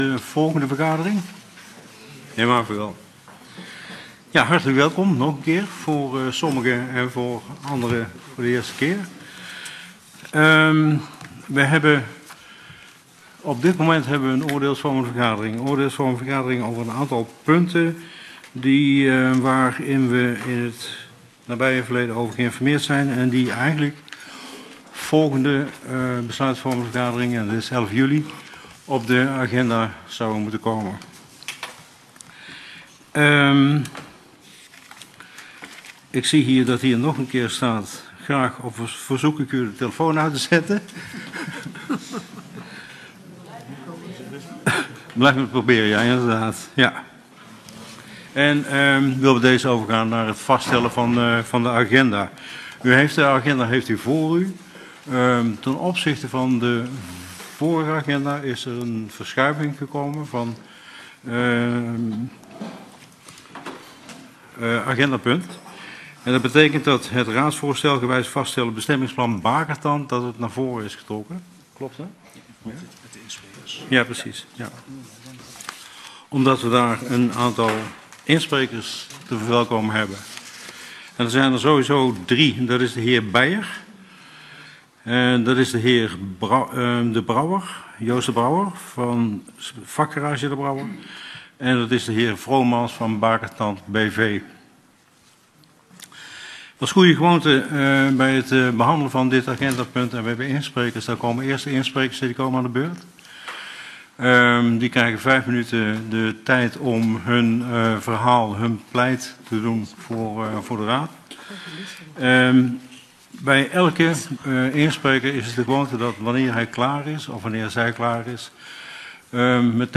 Deze vergadering zal plaatsvinden in de hal van het gemeentehuis.